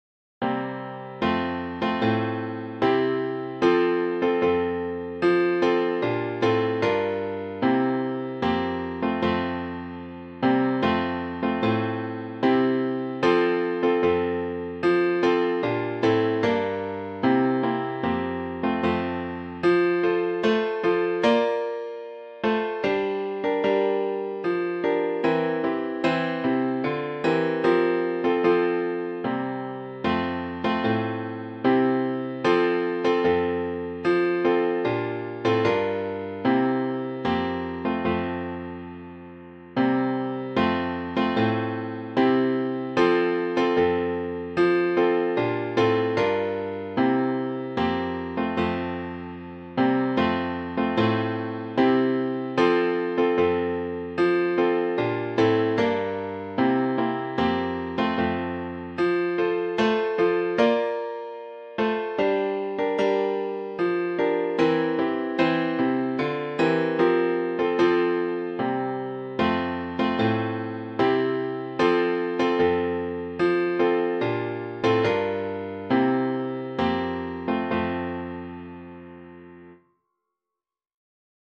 based on the folk melody
for piano